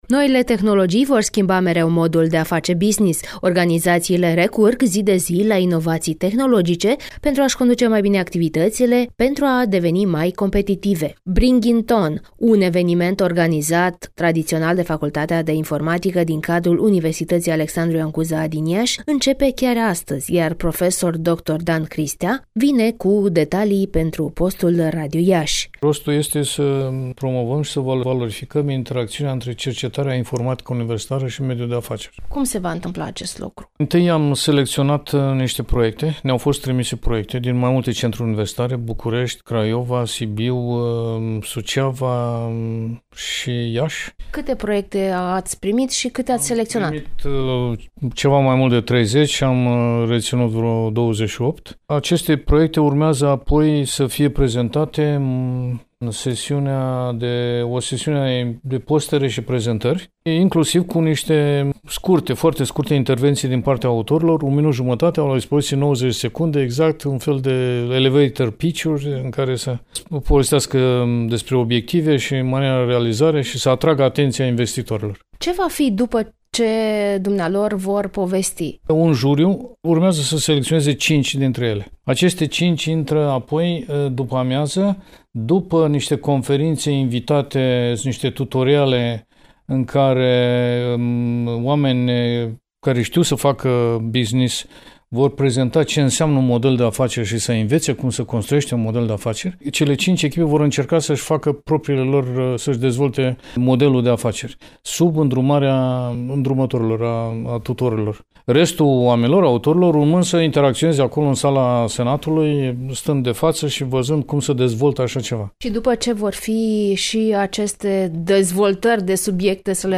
IAȘI: (Interviu) Cercetarea și inteligența artificială, dezbătute astăzi la Iași